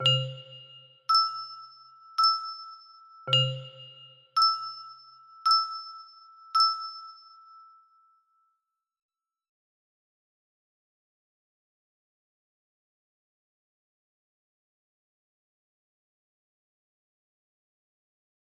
secret door music box melody
Grand Illusions 30 music boxes More